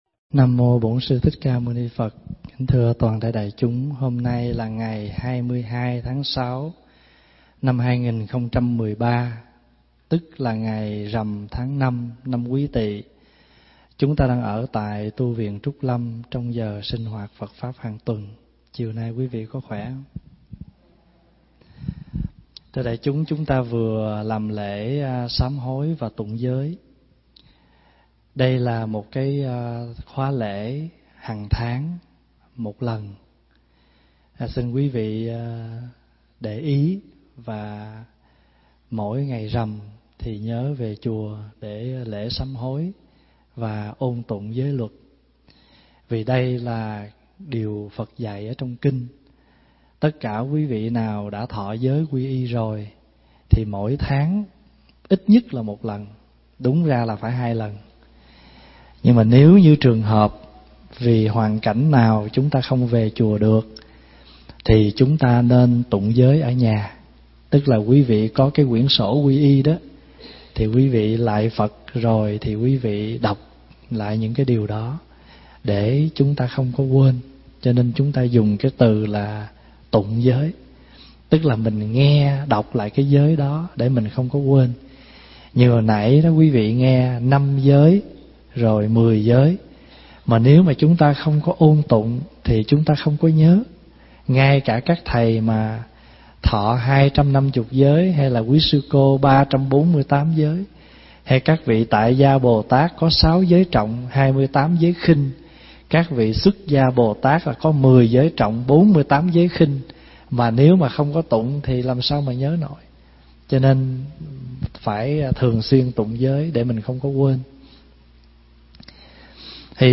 Mp3 Thuyết Giảng Nước Từ Rửa Sạch Oan Khiên 17
thuyết giảng tại Tu Viện Trúc Lâm, Canada